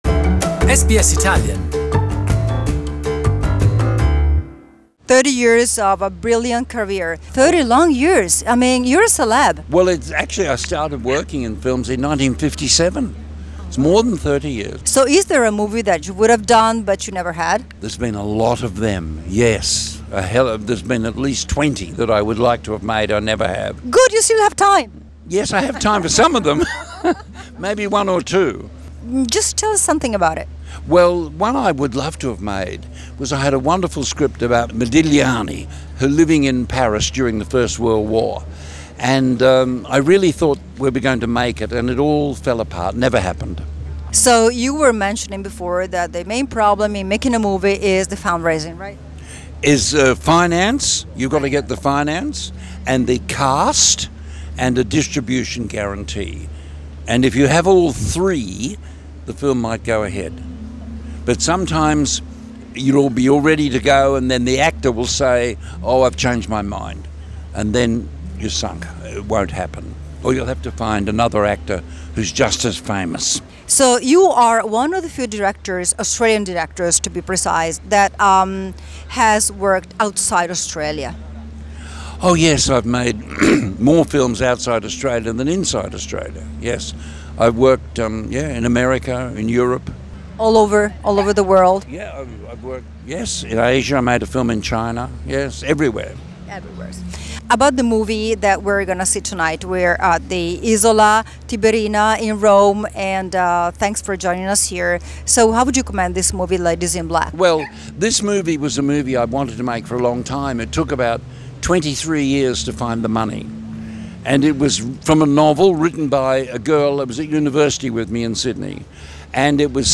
Bruce Beresford Interview